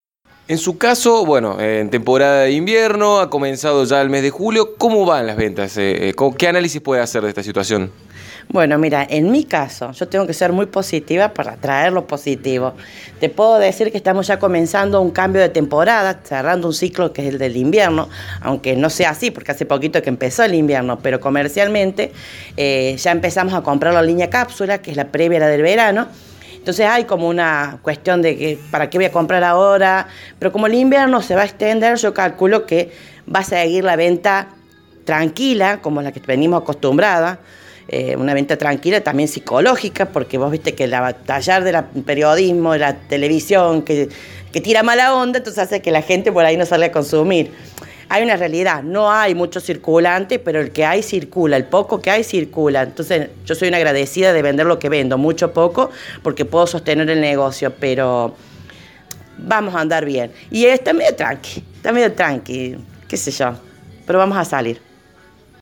FM Eco dialogó con comerciantes de la ciudad, quienes expresaron su preocupación por la acuciante situación económica y la marcada caída en las ventas.
Aquí los testimonios obtenidos: